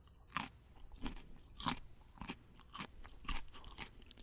吃水果 咀嚼声
描述：吃西瓜 声音比较脆